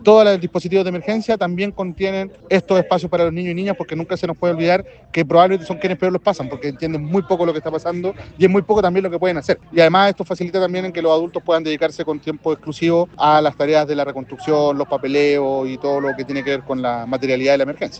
La escuela abierta busca contribuir al apoyo socioemocional, pero también facilitar que los apoderados, dijo el Ministro de Educación, Nicolás Cataldo, dediquen tiempo exclusivo a la reconstrucción y a los trámites que implica la recuperación de documentos.